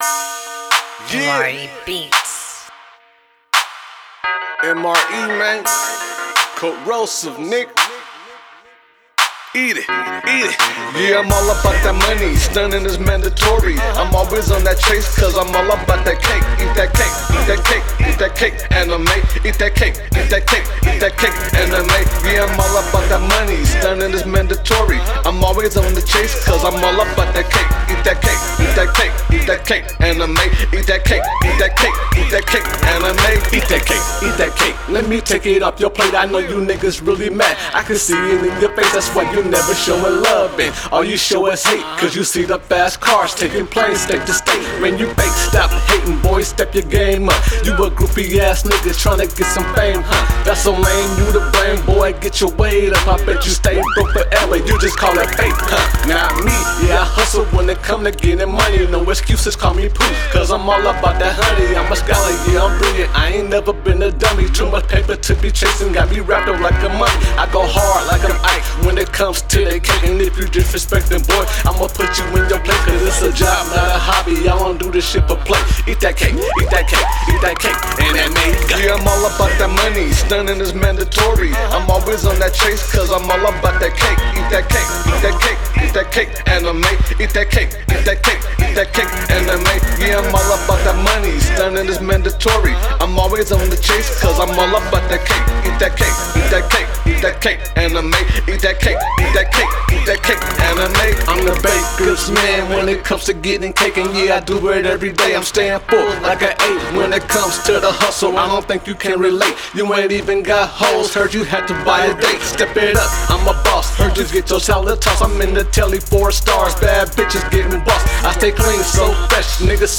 Hiphop
a club banger